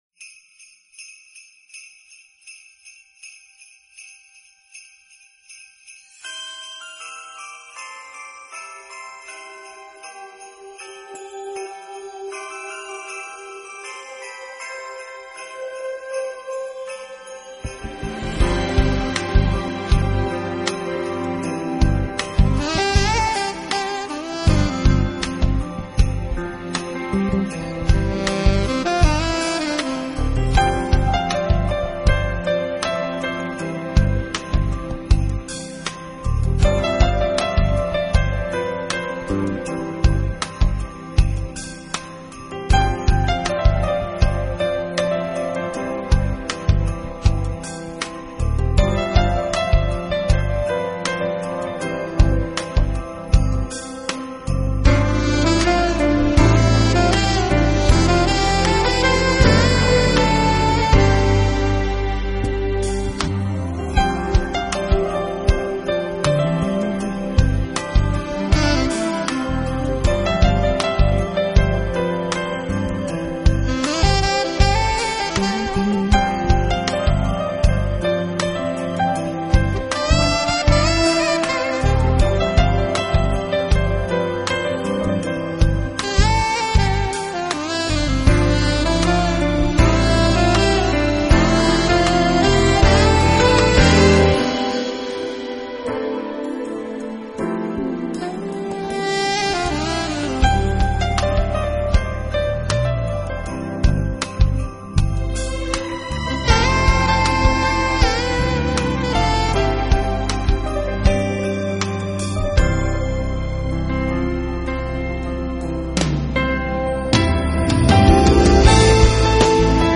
很轻快而温暖的味道!!他很懂得运用不同的乐器配搭去演绎这些音乐，带出不
这是一张十分有Night Mood的Smooth Jazz唱片, 能成功营造一个优美的音乐
意境的唱片，音乐带了点R&B的味道，有着一份独特的音乐格调!!